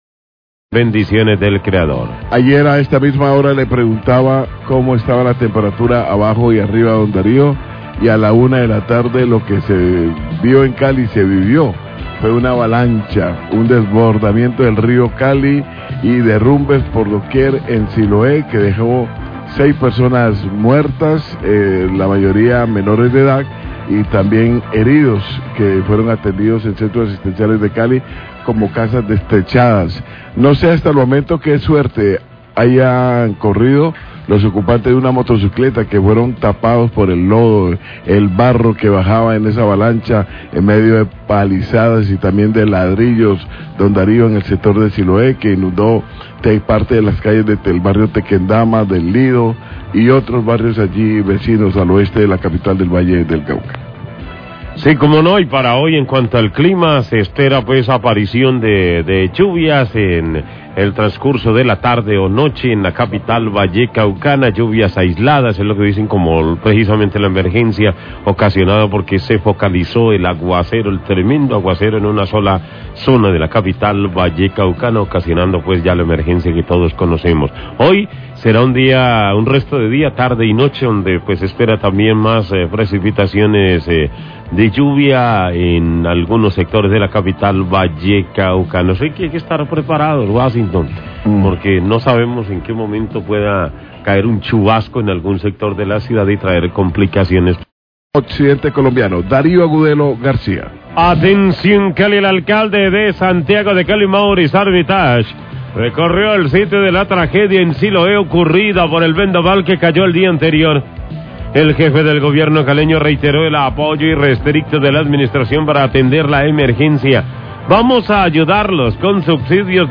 Radio
Noticias de Calidad informan sobre los hechos ocurridos en Siloé tras el fuerte aguacero del día de ayer y, recoge las declaraciones del Alcalde Armitage donde da su balance acerca de la situación de la zona de tragedia y las acciones a seguir. De la misma manera el secretario de infraestructura expresó que algunas casas presentan daños en su estructura que deben ser reparadas inmediatamente, o en su defecto harán lo posible por reubicar a los habitantes.